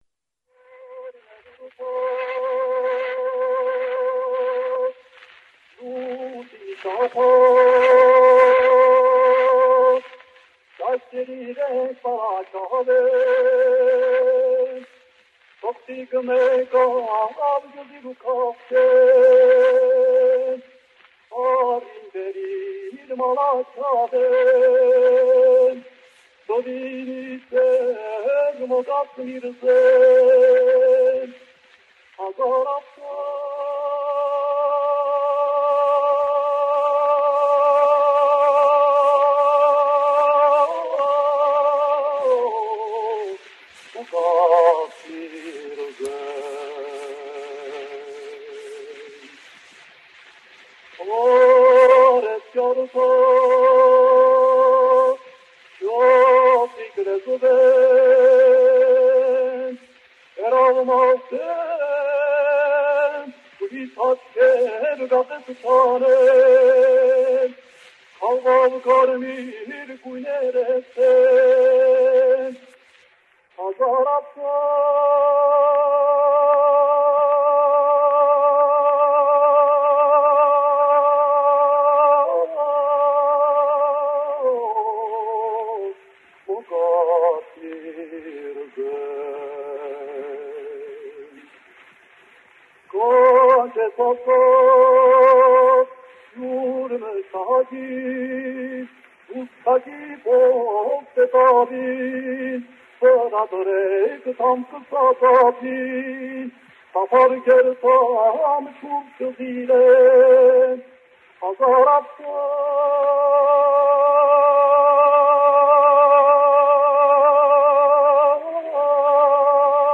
Песню "Мокац Мирза" на этой записи исполняет Комитас.